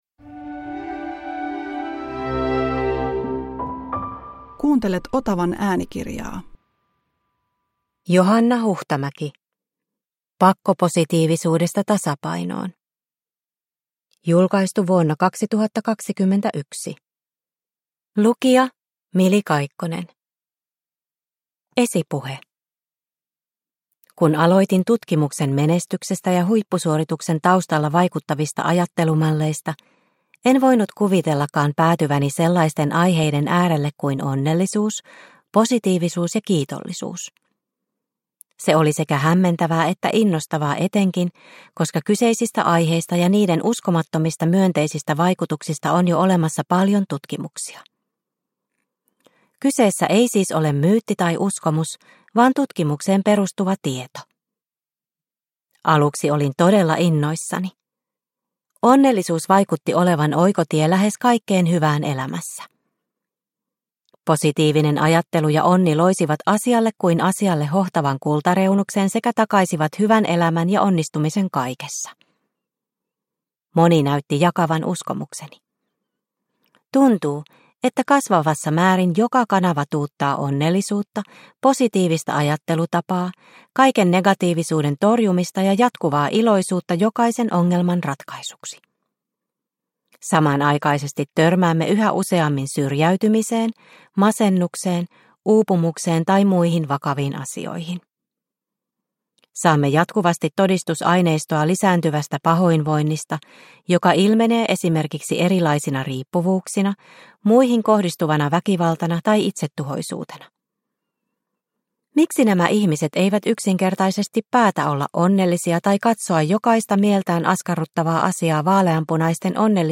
Pakkopositiivisuudesta tasapainoon – Ljudbok – Laddas ner